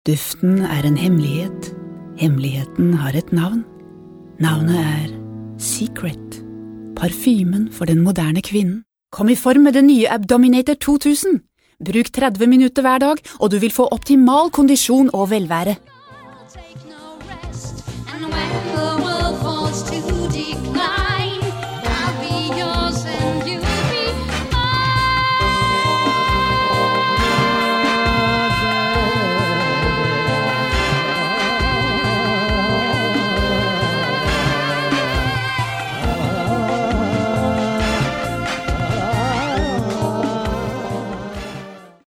Sprecherin norwegisch (Muttersprache) Sprecherin für Trickfilme, Werbung, Dokumentationen uvm.
Sprechprobe: Industrie (Muttersprache):
Norvegian female voice over artist.